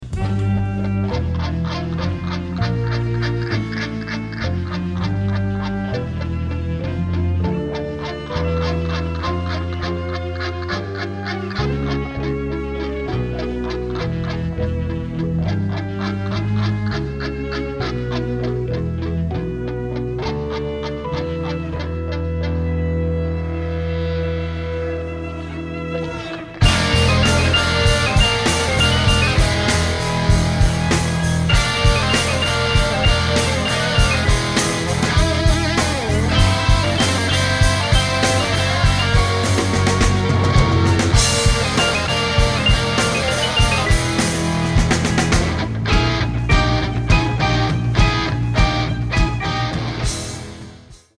progressive rock music